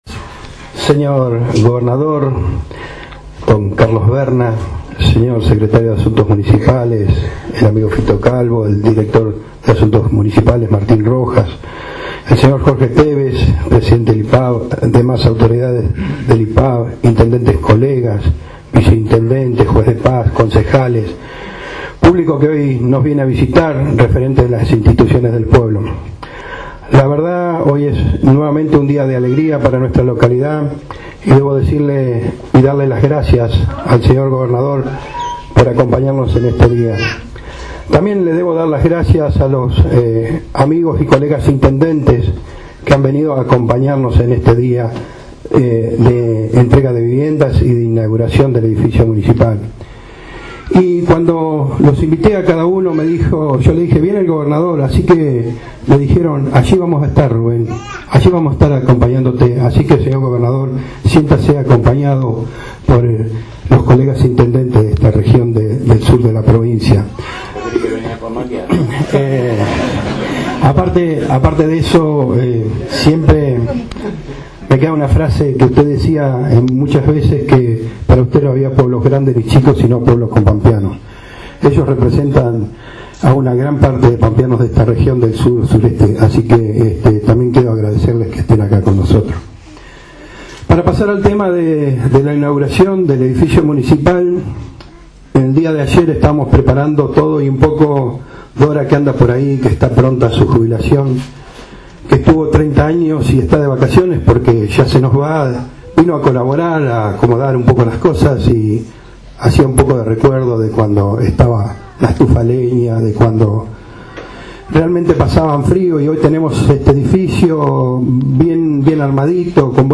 El gobernador de la Provincia, Carlos Verna, participó hoy en la localidad de Alpachiri, de la inauguración del nuevo edificio municipal, y de la entrega de 4 viviendas del Plan Plurianual, 3ª etapa y 1 del Programa Soluciones Habitacionales.